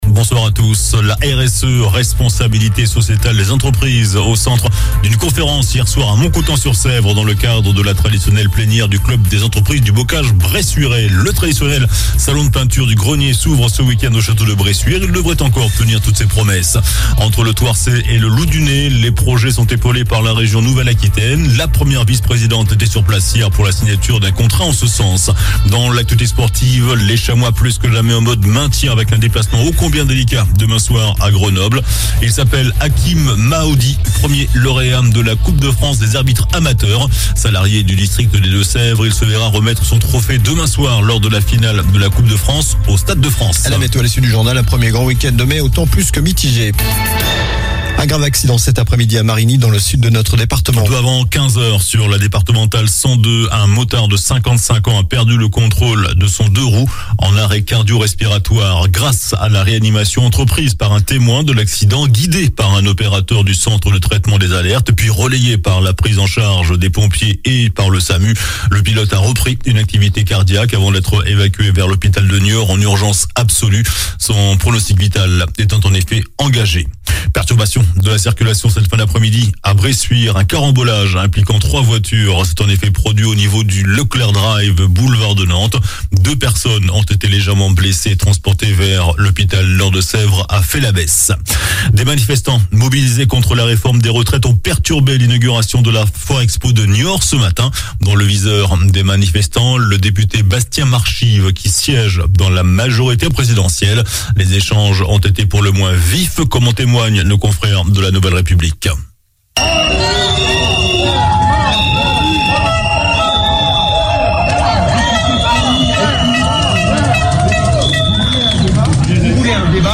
JOURNAL DU VENDREDI 28 AVRIL ( SOIR )